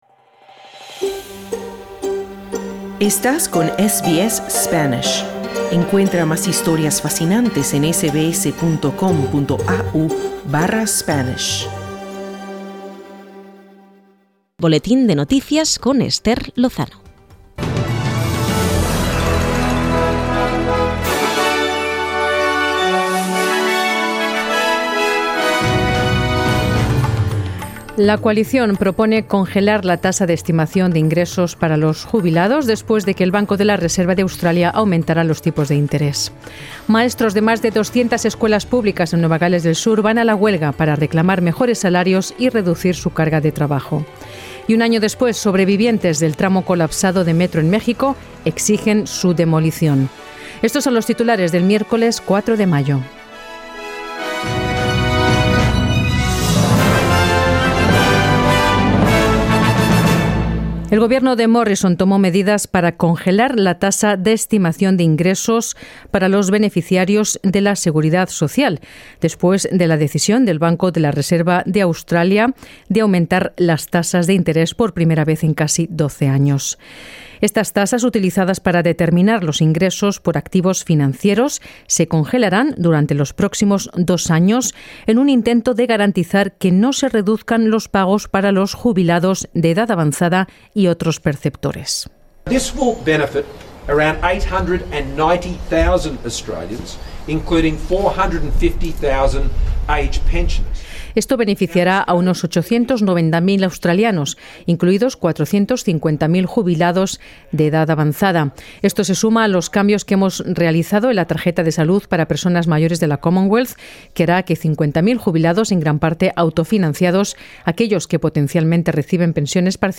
Noticias SBS Spanish | 4 mayo 2022